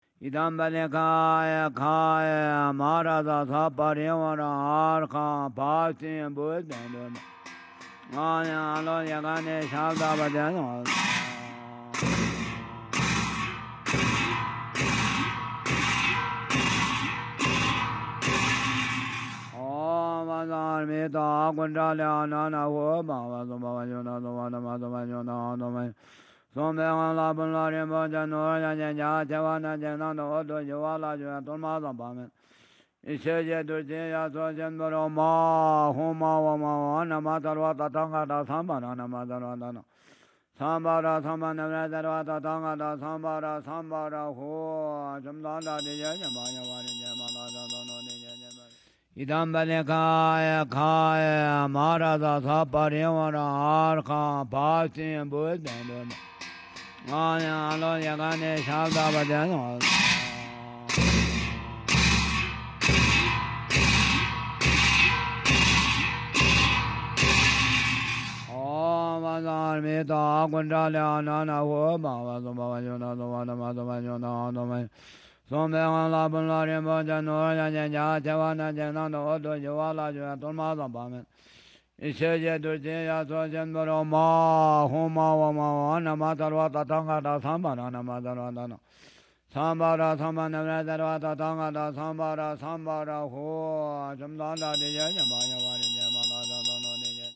Buddhist chanting at Ladakh
Stereo 48kHz 24bit.
recitation of sacred Buddhist texts in the trans-Himalayan Ladakh region, Jammu and Kashmir, India